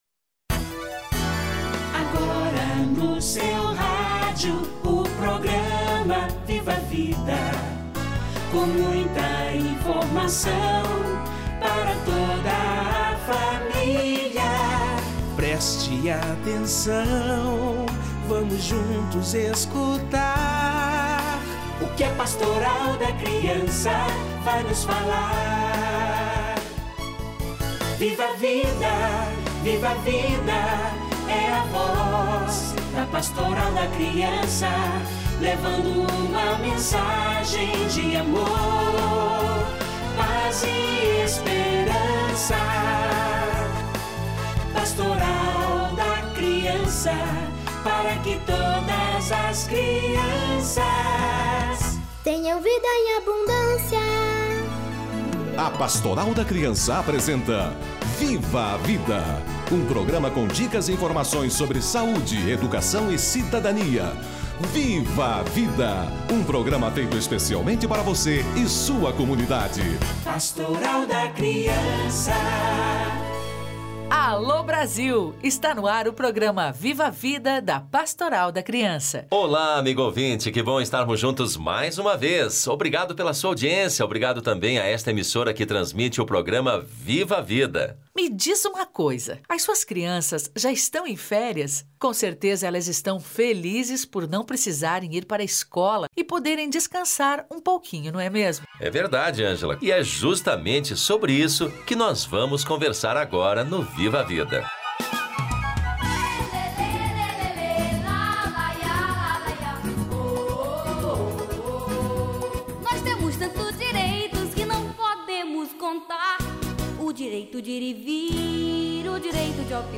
Prevenção de acidentes nas férias - Entrevista